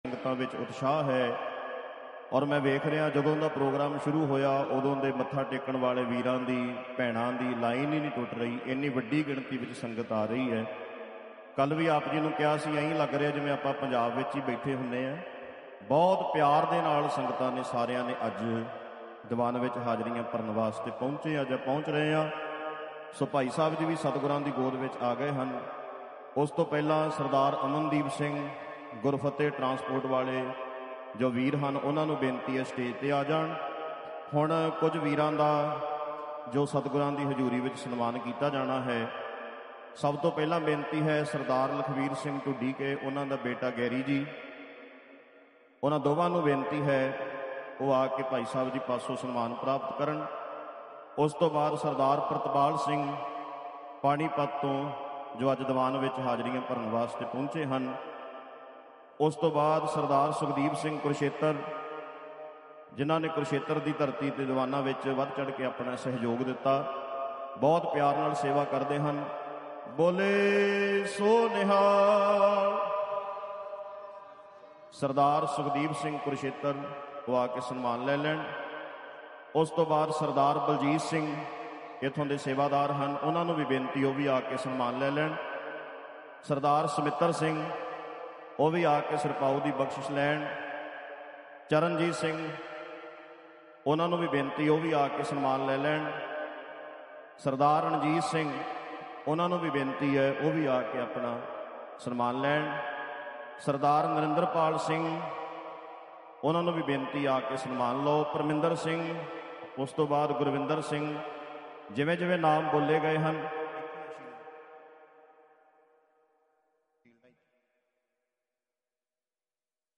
Live Aweer Ras Al Khor dubai gurmat samagam 27 sep 2025